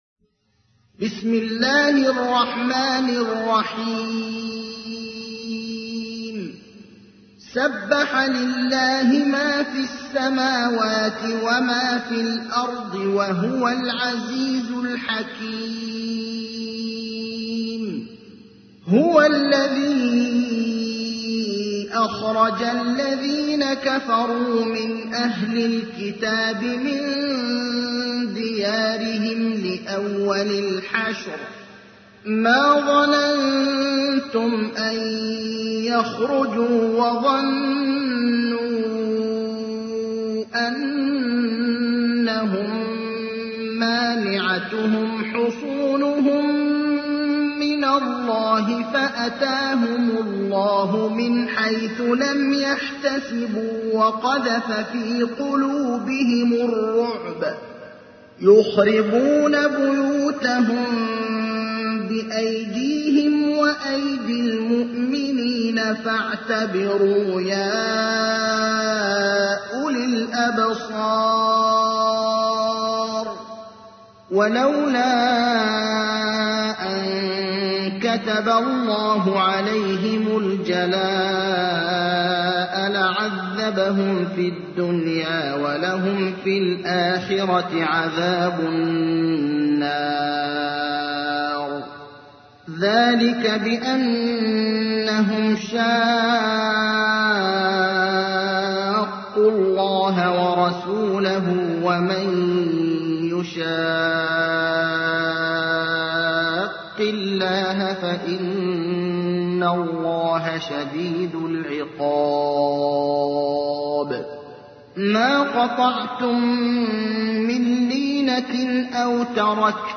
تحميل : 59. سورة الحشر / القارئ ابراهيم الأخضر / القرآن الكريم / موقع يا حسين